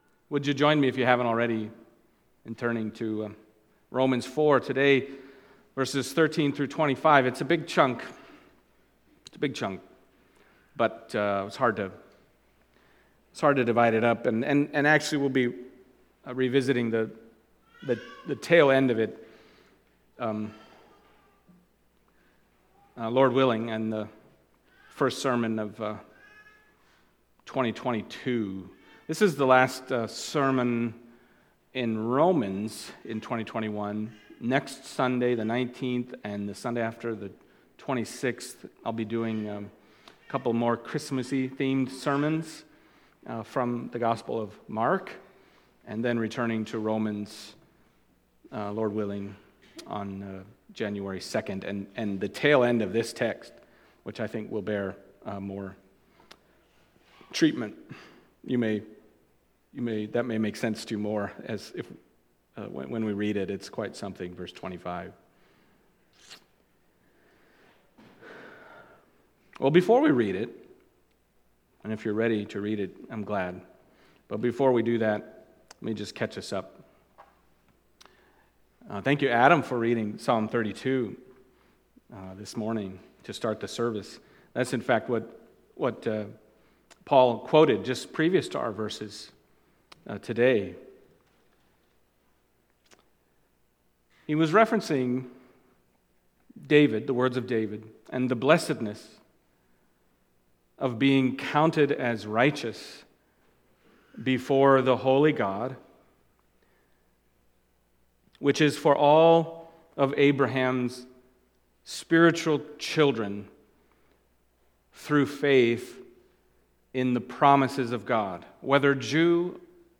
Romans Passage: Romans 4:13-25 Service Type: Sunday Morning Romans 4:13-25 « Abraham